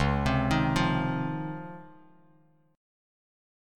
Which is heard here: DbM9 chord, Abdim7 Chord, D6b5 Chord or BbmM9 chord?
DbM9 chord